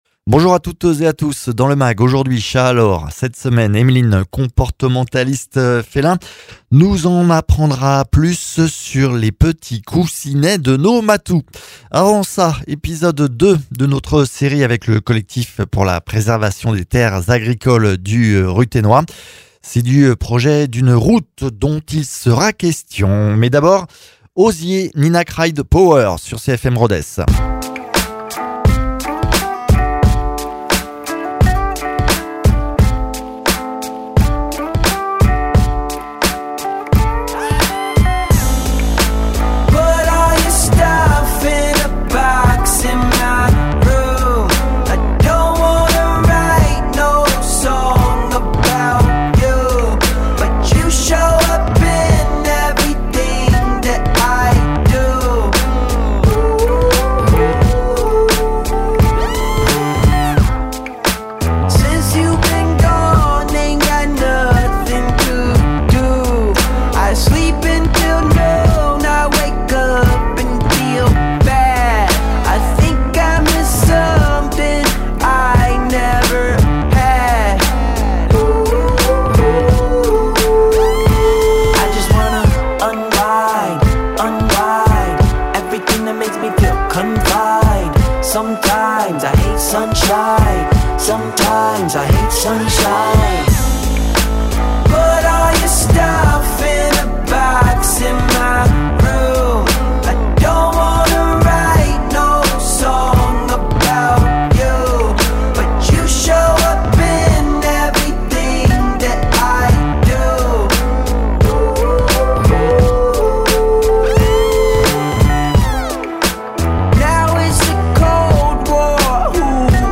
comportementaliste félin